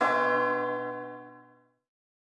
Media:Mortis_base_dep.wavMedia:Mortis_evo1_dep.wavMedia:Mortis_evo2_dep.wav 部署音效 dep 在角色详情页面点击初级、经典、高手和顶尖形态选项卡触发的音效
Mortis_base_dep.wav